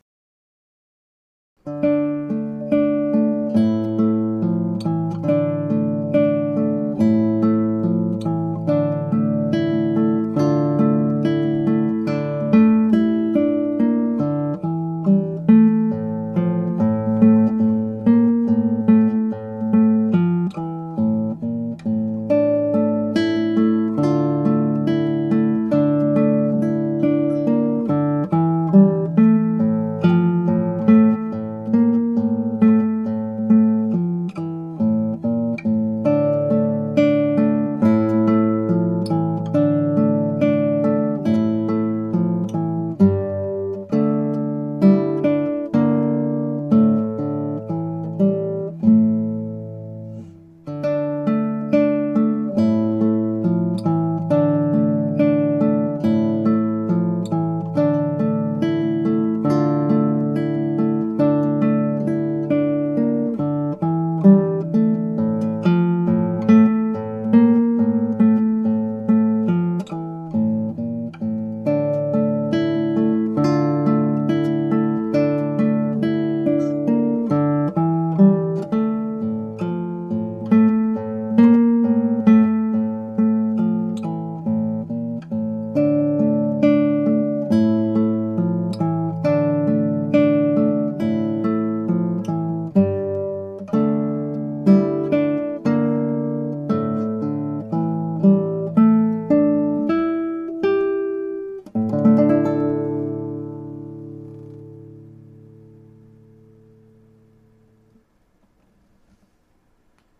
Guitar amatuer play